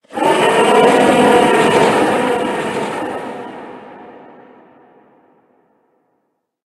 Cri de Corvaillus Gigamax dans Pokémon HOME.
Cri_0823_Gigamax_HOME.ogg